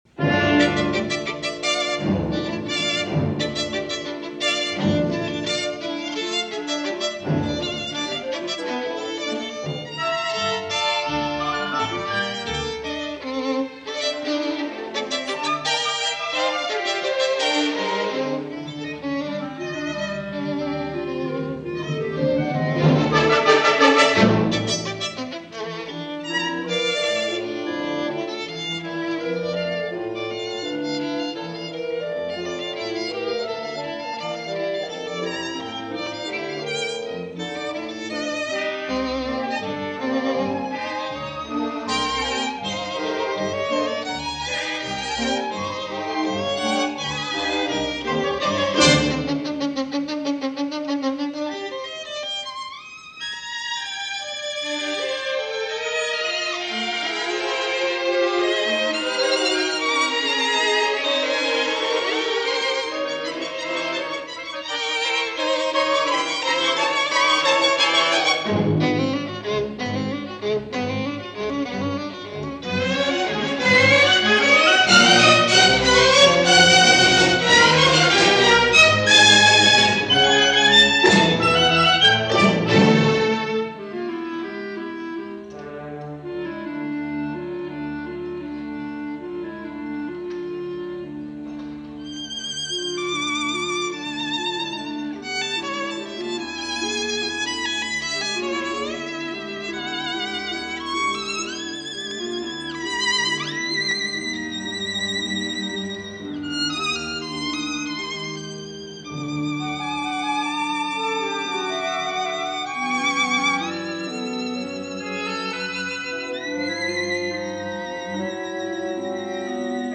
violin
1958 CBC Studio recording
Clarity of texture and economy of material.